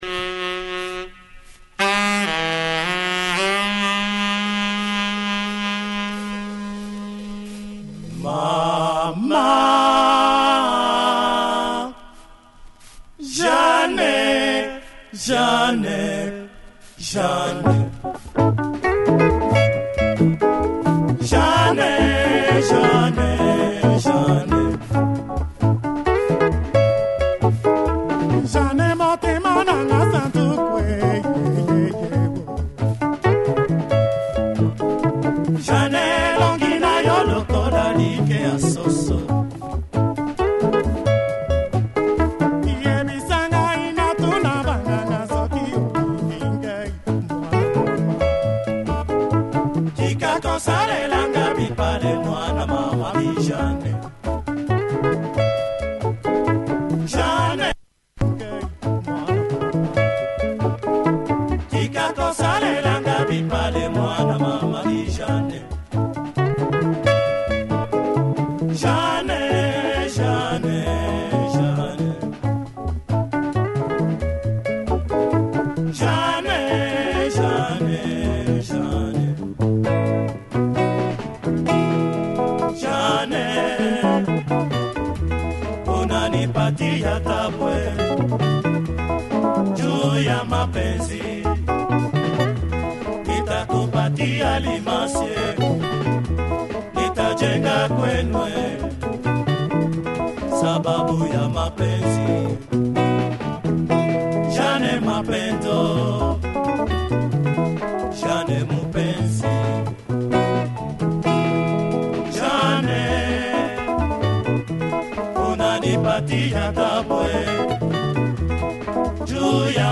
good bounce, great sax